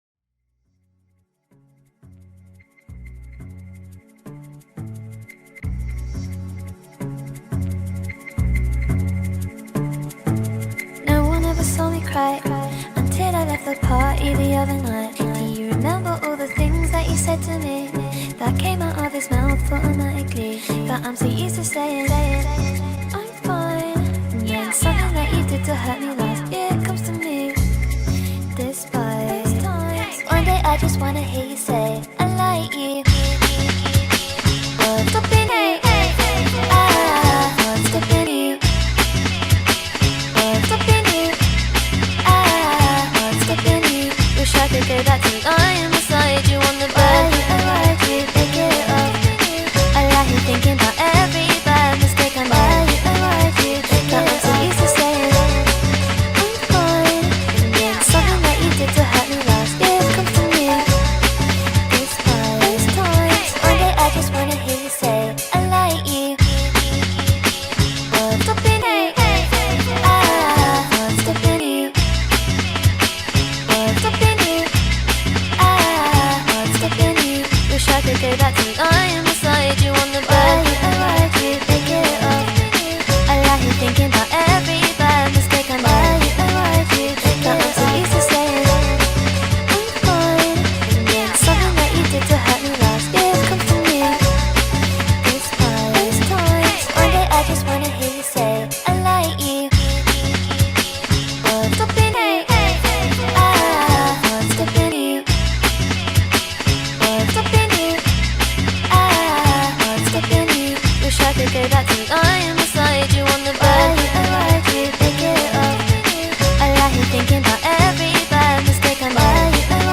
this has a female vocal